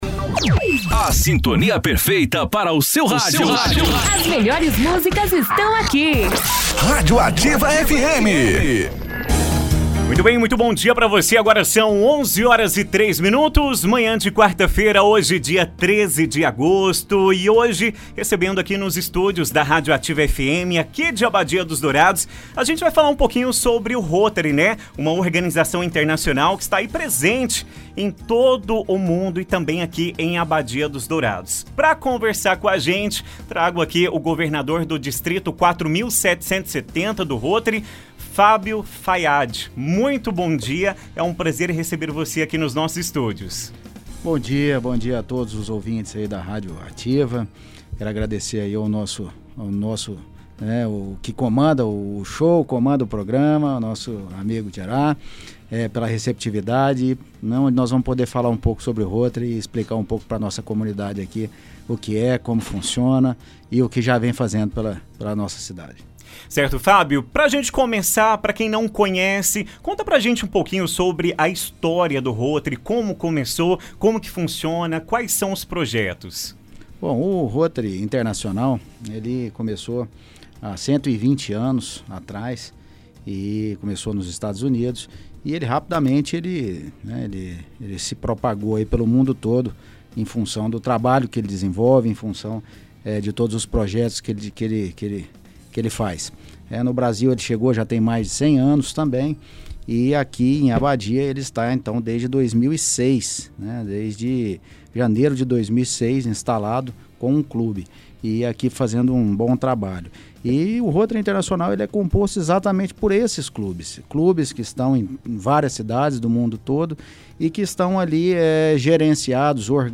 Entrevista na íntegra:
Ontem, 13 de agosto, a Rádio Ativa FM teve a honra de receber em seu estúdio duas importantes lideranças rotárias para uma entrevista especial.
ENTREVISTA-ROTARY.mp3